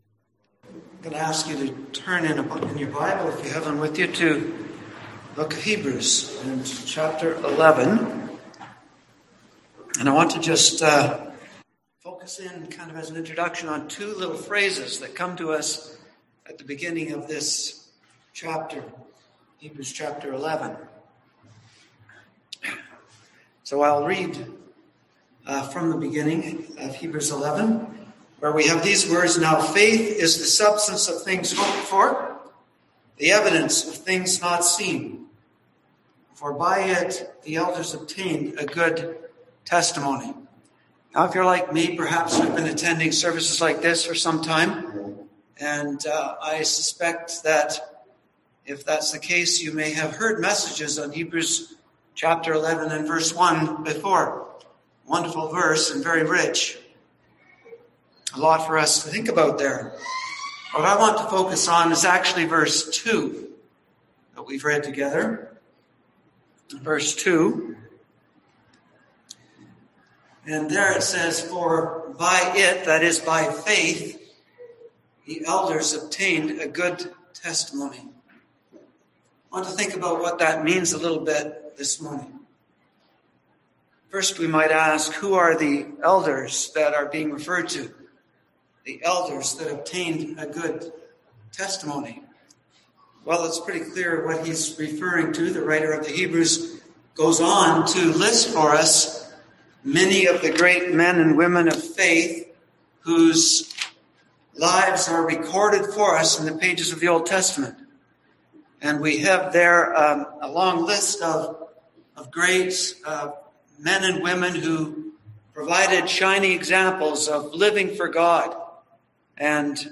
Series: OT Faith through NT Lens Passage: Hebrews 11 Service Type: Sunday AM